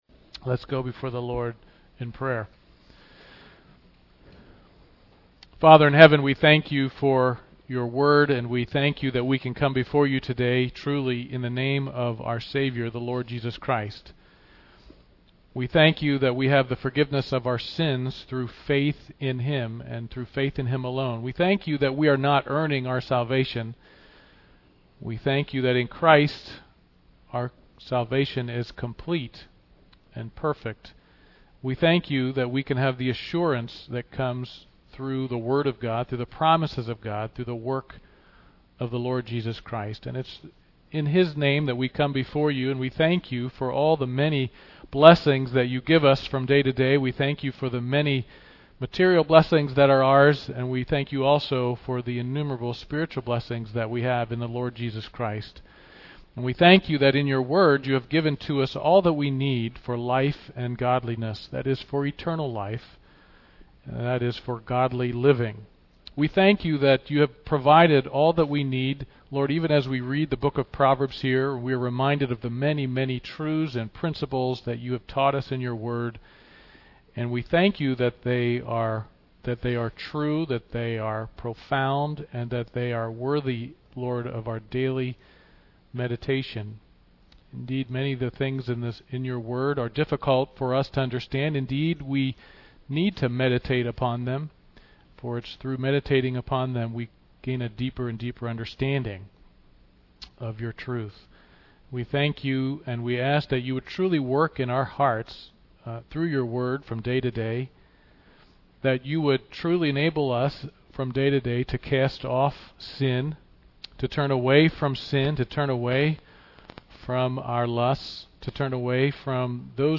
Service Type: Sunday Morning Worship Topics: Heaven , Providence , Worship